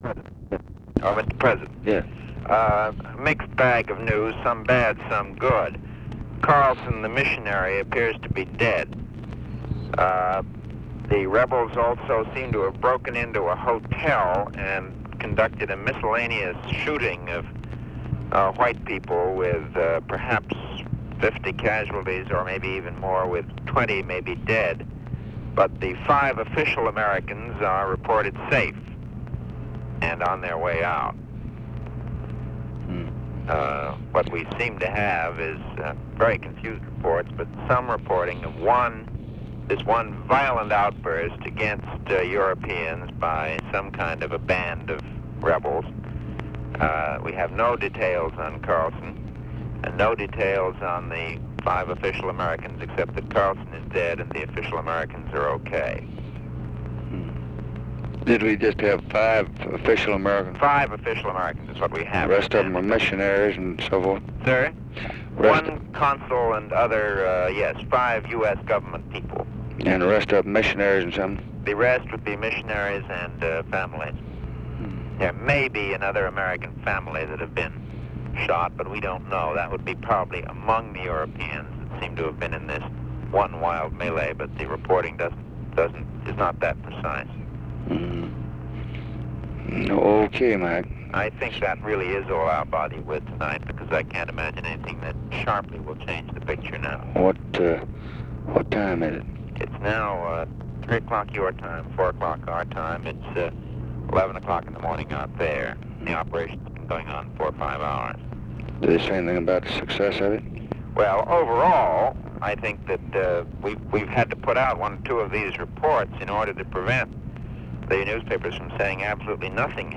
Conversation with MCGEORGE BUNDY, November 24, 1964
Secret White House Tapes